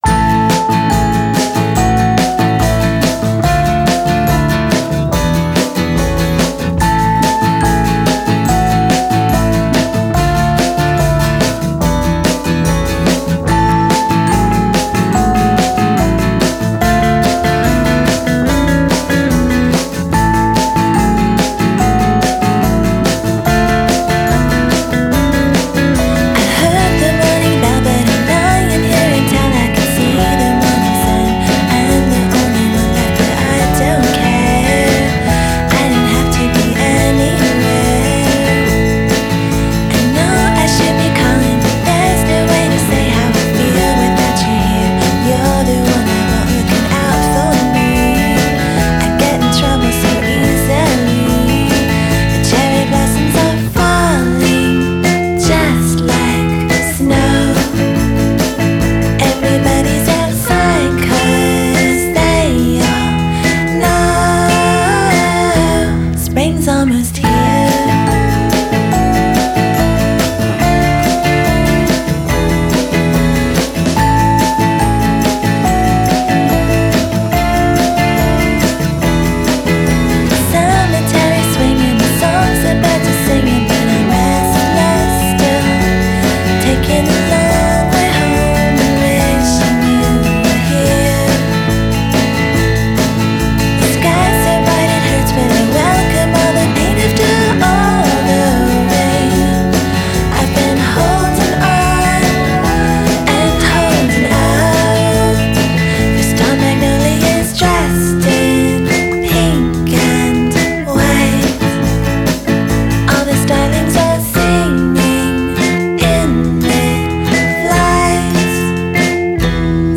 vocals, acoustic guitar
drums
bass
viola, percussion, vocals
rhodes piano
Genre: Indie Pop / Twee / Female Vocal